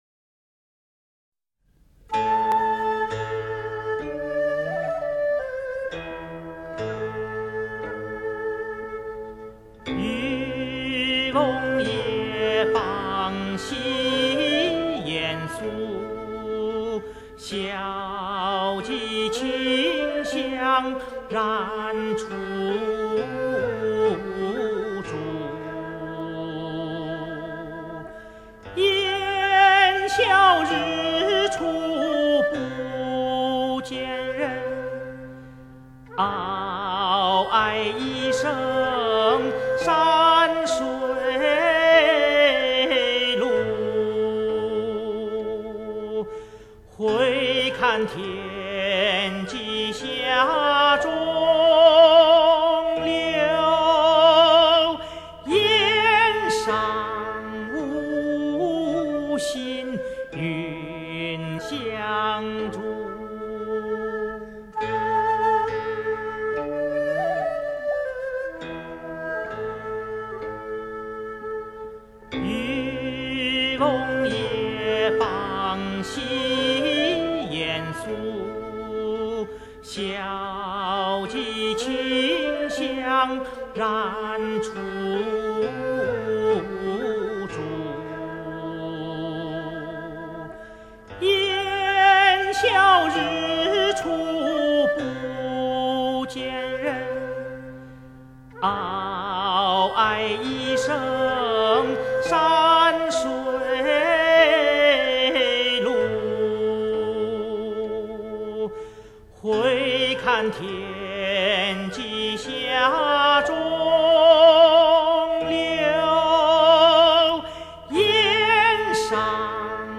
[2006-10-16]【欣赏】姜嘉锵演唱的古曲《渔歌调》